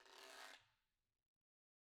Guiro-M_v1_Sum.wav